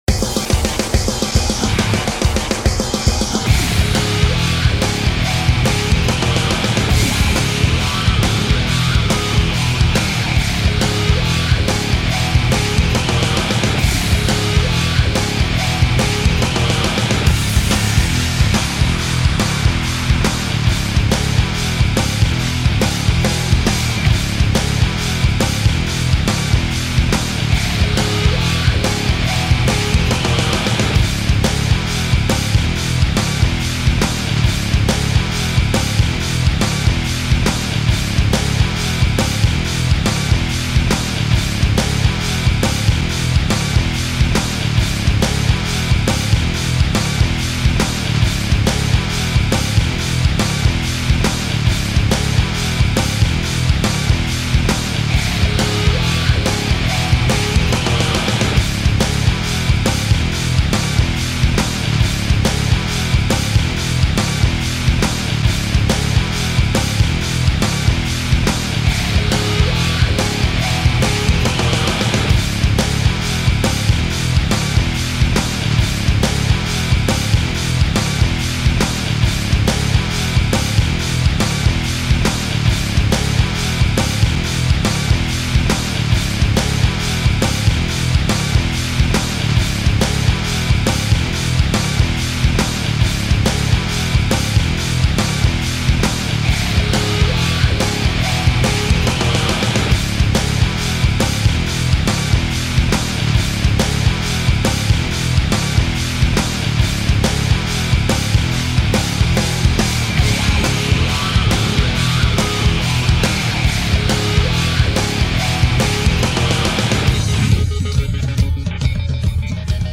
The finished version is just an instrumental loop (that I'll probably need to change for the YouTube release due to bots).